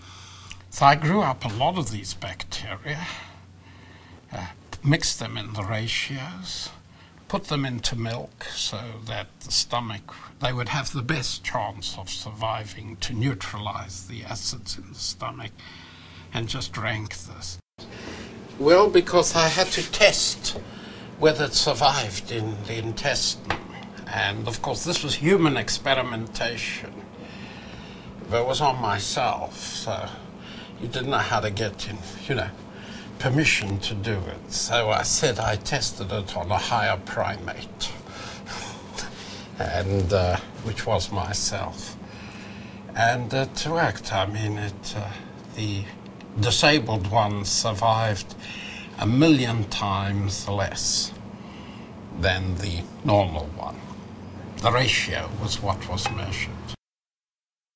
Interviewee: Sydney Brenner.